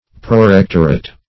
Prorectorate \Pro*rec"tor*ate\, n.